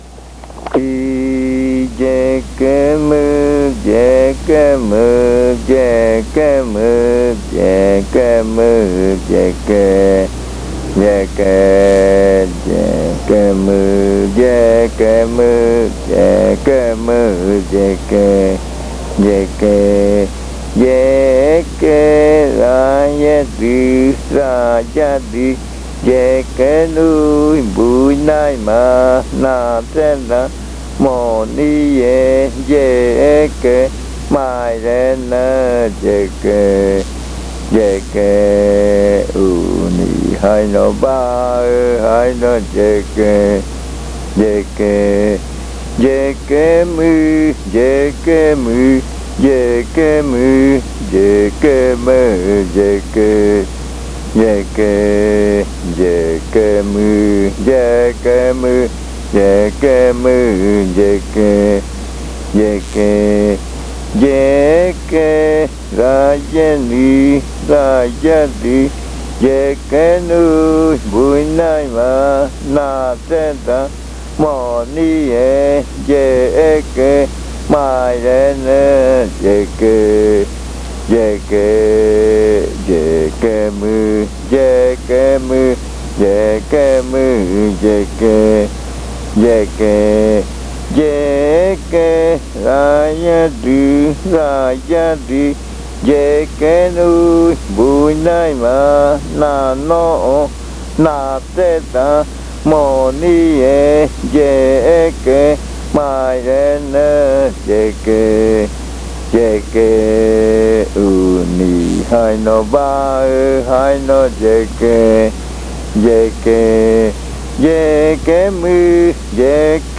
Leticia, Amazonas
This chant is part of the collection of chants from the Yuakɨ Murui-Muina (fruit ritual) of the Murui people